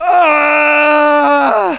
manscream2.mp3